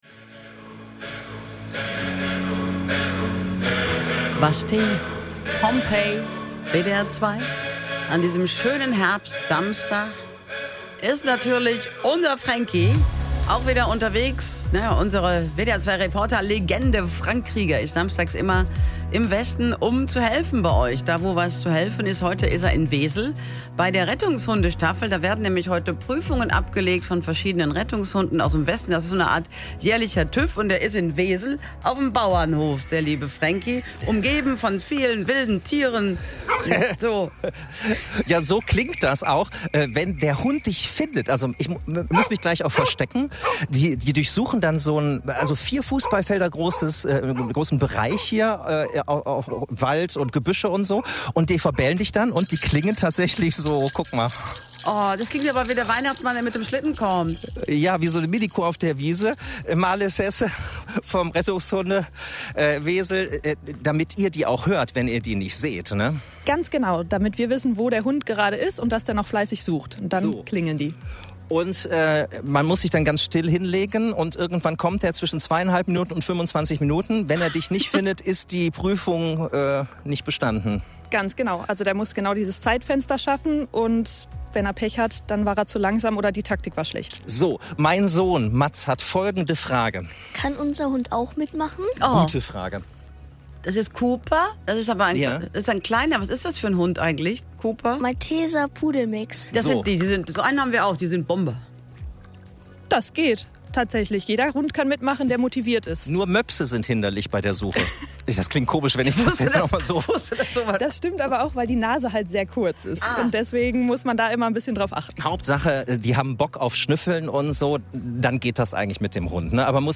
WDR 2 begleitet die diesjährige Herbstprüfung!